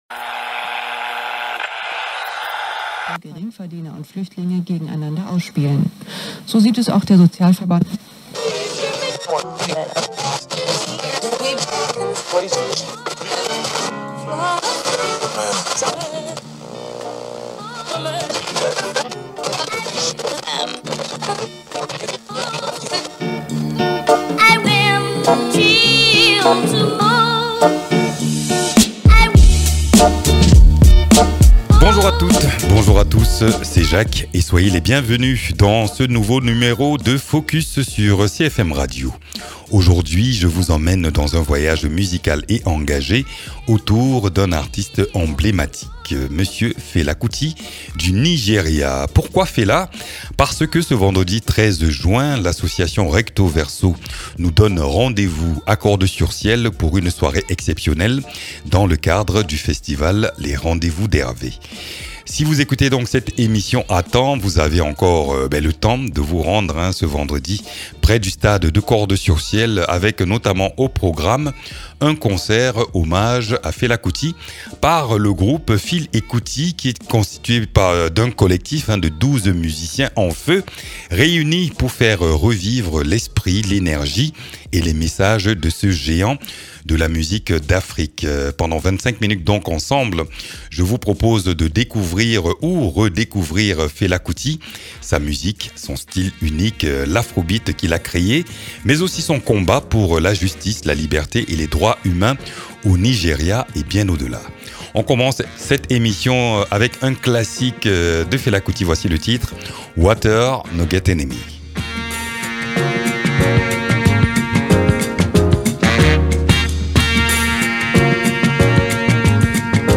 C’est un univers envoûtant et engagé dans lequel on pénètre, à travers quelques titres phares, des anecdotes et une immersion dans son héritage musical et politique.